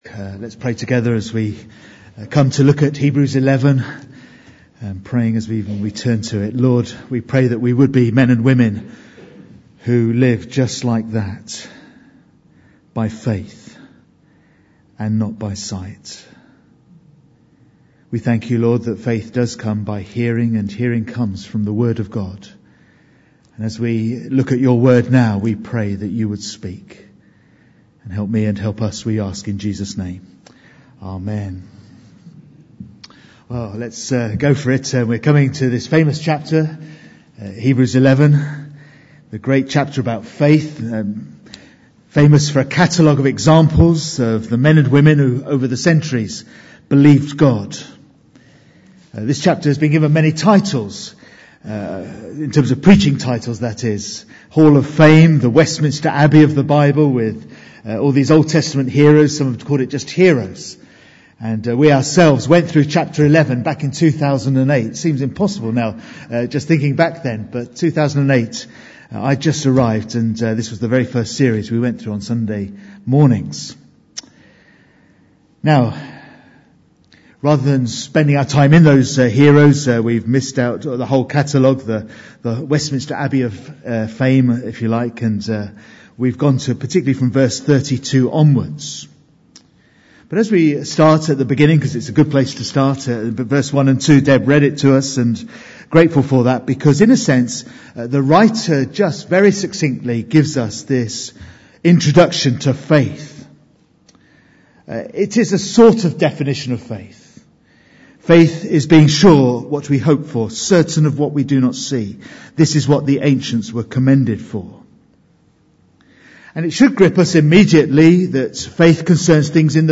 Bible Text: Hebrews 11:1-2, Hebrews 11: 32-40 | Preacher